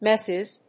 /ˈmɛsɪz(米国英語), ˈmesɪz(英国英語)/